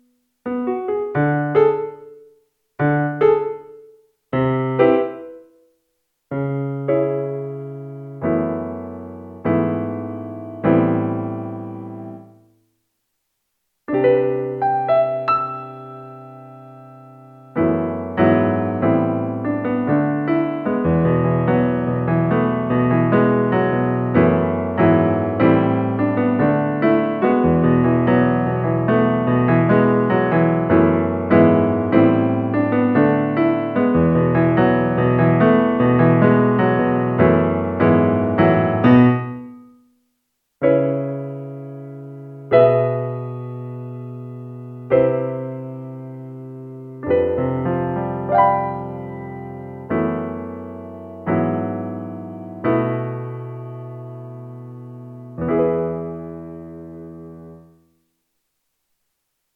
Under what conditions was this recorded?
60-second audition cut